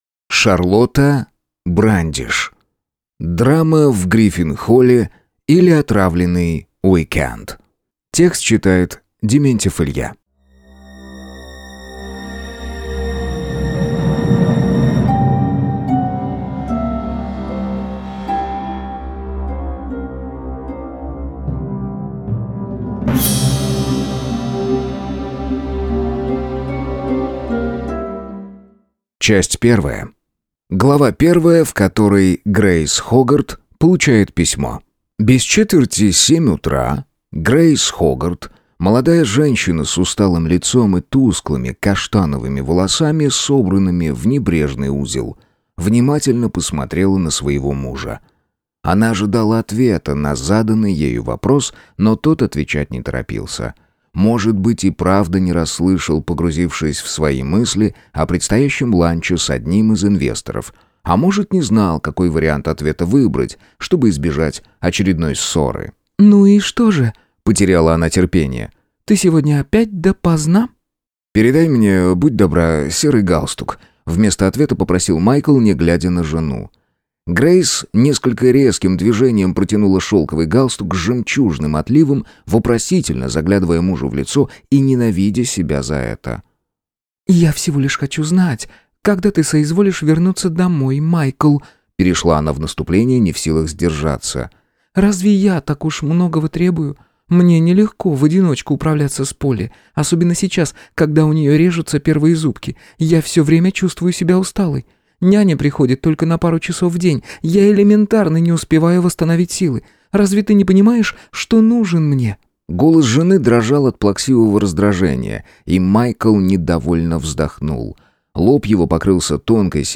Аудиокнига Драма в Гриффин-холле, или Отравленный уикенд | Библиотека аудиокниг